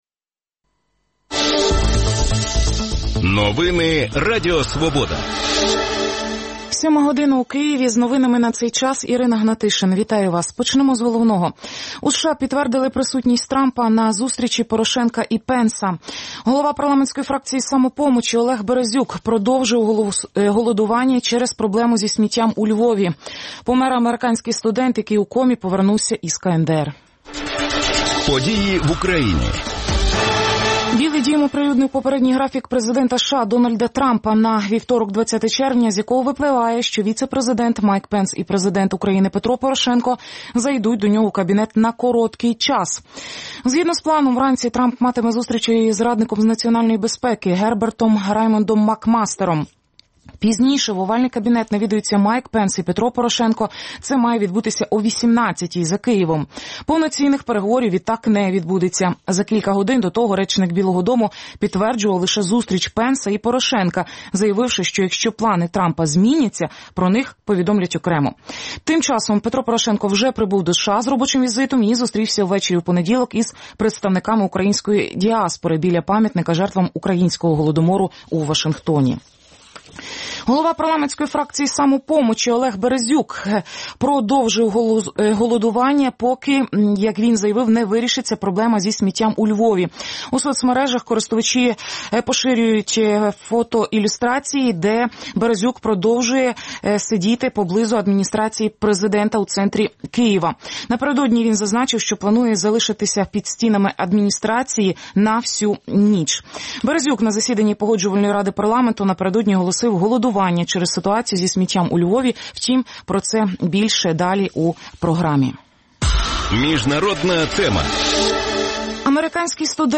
гості студії